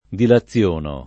dilaziono [ dila ZZL1 no ]